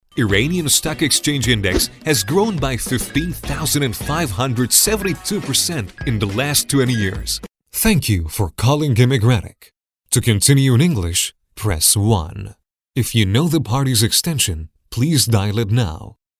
Male
Young
Adult
English-Persian-Accent